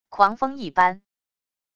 狂风一般wav音频